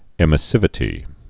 (ĕmĭ-sĭvĭ-tē)